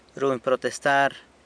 runy protestar[ruhny protestaar]